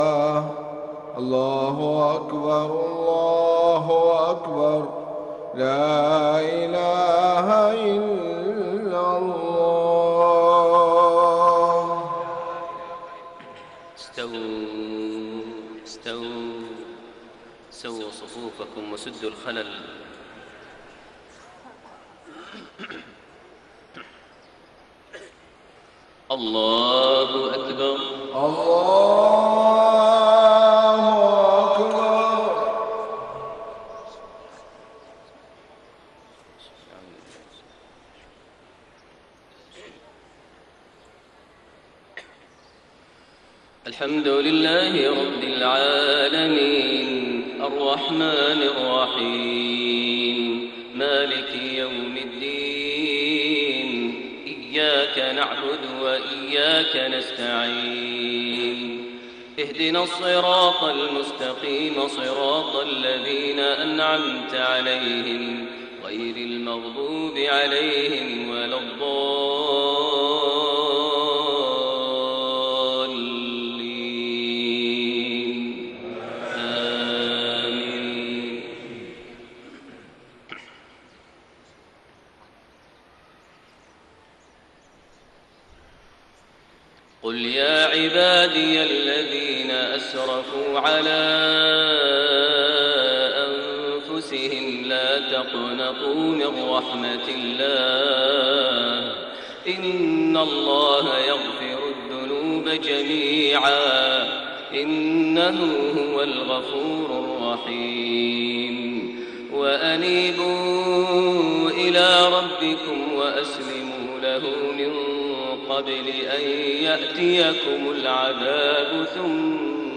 صلاة الفجر 9 ذو الحجة 1432هـ خواتيم سورة الزمر 53-75 > 1432 هـ > الفروض - تلاوات ماهر المعيقلي